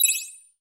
Simple Digital Connection 3.wav